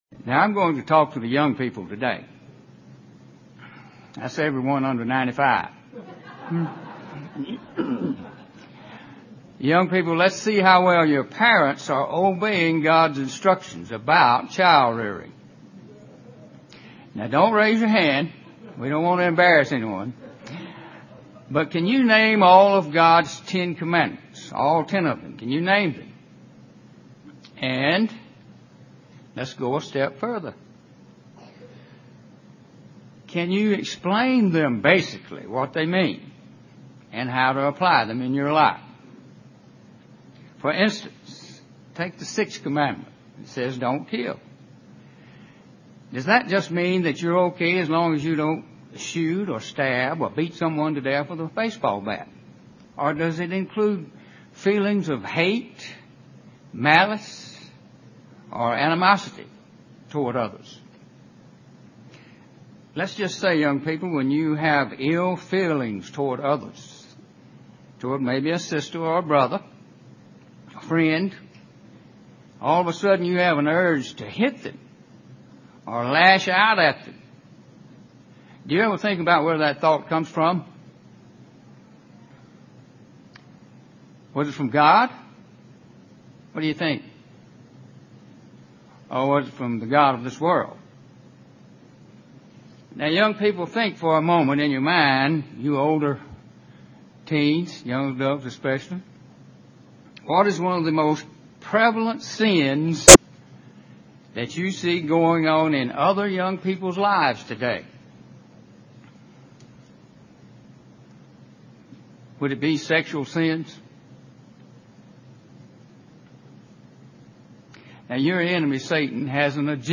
Given in Columbus, GA
UCG Sermon Studying the bible?